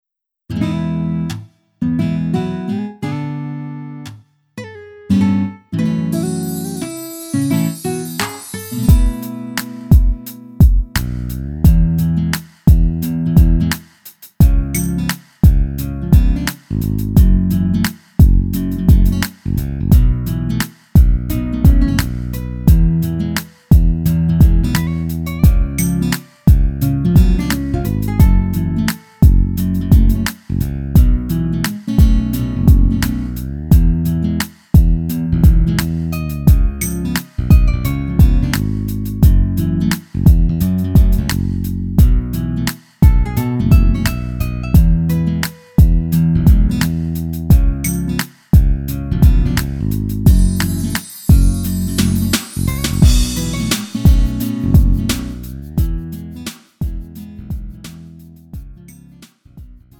음정 원키 3:45
장르 가요 구분 Lite MR